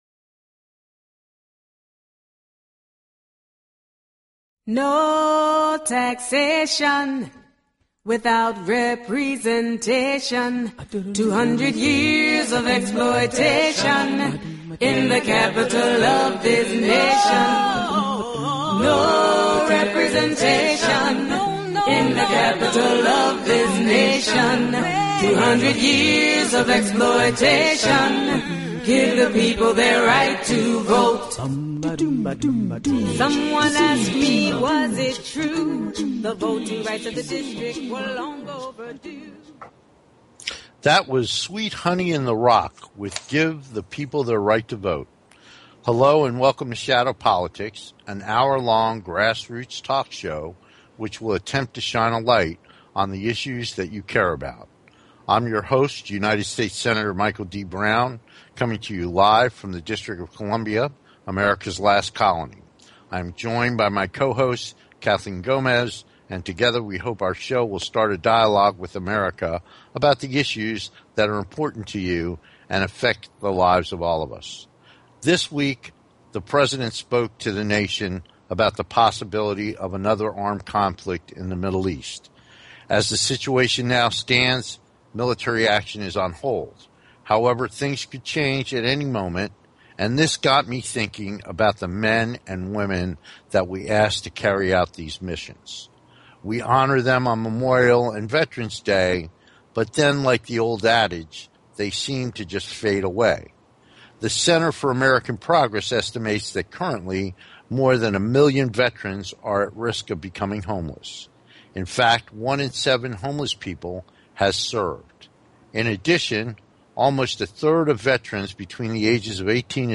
The show is not only informative but intertaining, and they both convey their opinions with humor, wit and a strong rapport.
Shadow Politics is a grass roots talk show giving a voice to the voiceless.
We look forward to having you be part of the discussion so call in and join the conversation.